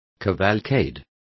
Complete with pronunciation of the translation of cavalcades.